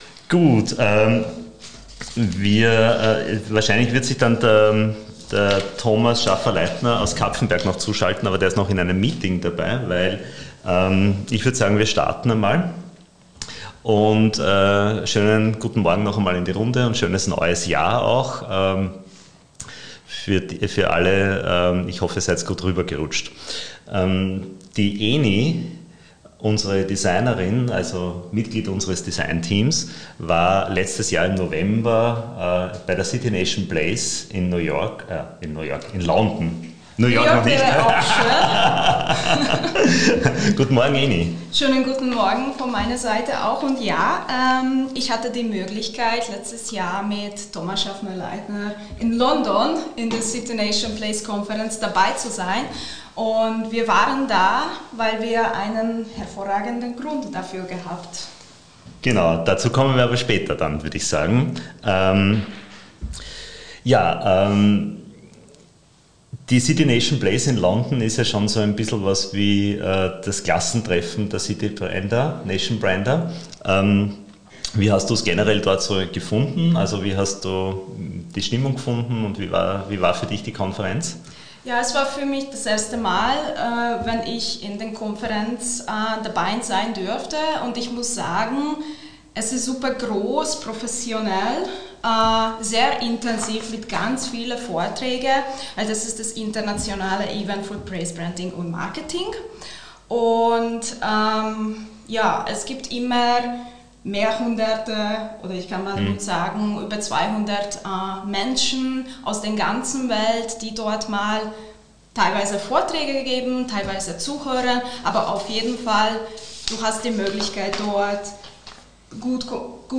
Das ganze Gespräch können Sie hier nachhören, die wesentlichen Punkte haben wir für Sie zusammengefasst.